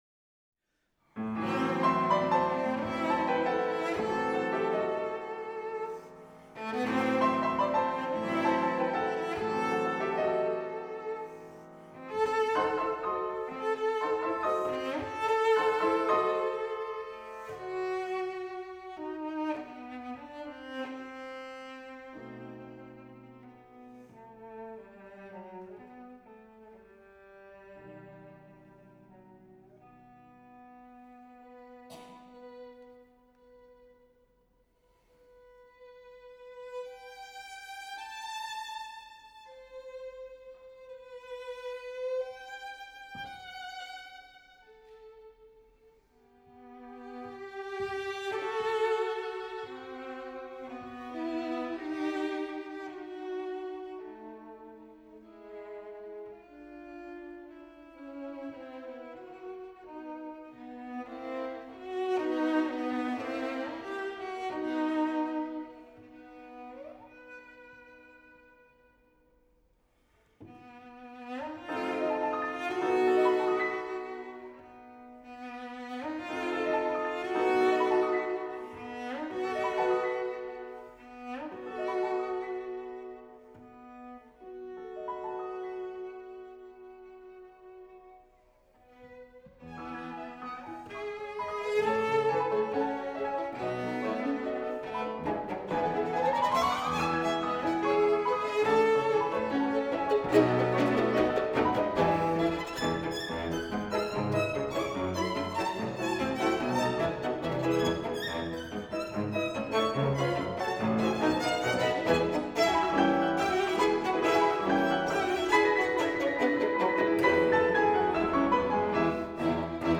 Piano Trio No 4 in E minor ‘Dumky’ Lento maestoso – Allegro quasi doppio movimento – Lento maestoso – Allegro Poco adagio – Vivace non troppo – Poco adagio – Vivace Andante – Vivace non troppo – Andante – Allegretto Andante moderato (quasi tempo di marcia) – Allegretto scherzando – Tempo1 – Allegro – Tempo1 […]
Venue: St. Brendan’s Church
Instrumentation: vn, vc, pf Instrumentation Category:Piano Trio